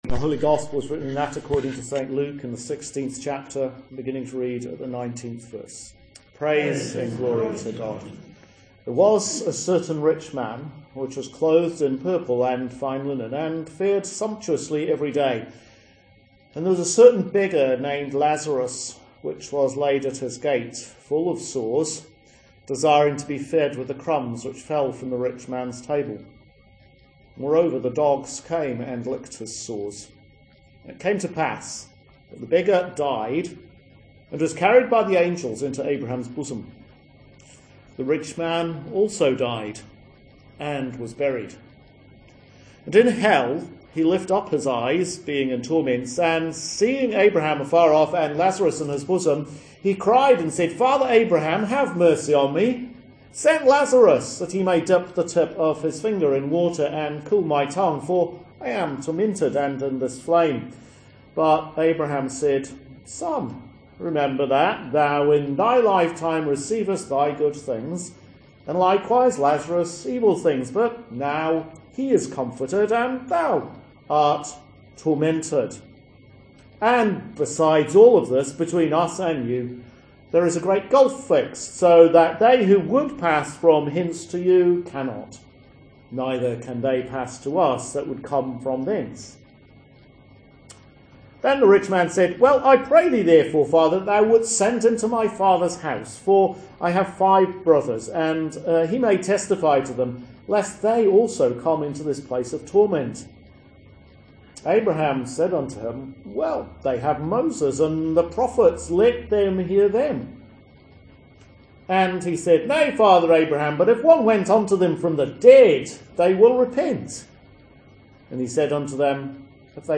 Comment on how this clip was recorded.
Sermon at Mid-Week Eucharist Wednesday June 1, 2016